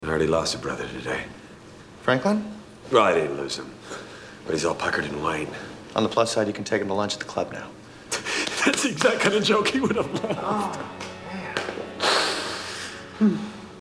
Category: Television   Right: Personal
Tags: Micheal from Arrested Development Arrested Development Arrested Development sounds Arrested Development clips Jason Bateman